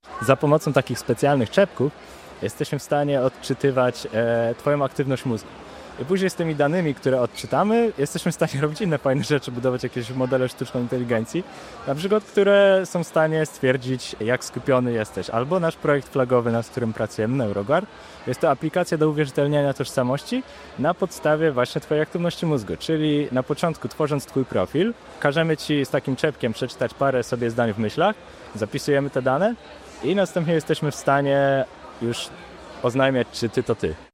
W środę, 29 października, wybraliśmy się na miejsce, żeby dać głos przedstawicielom kół naukowych.